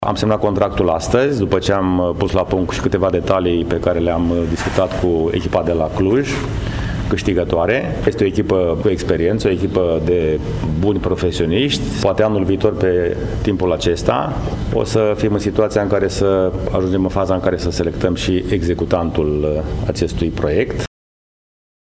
George Scripcaru, primar Brașov: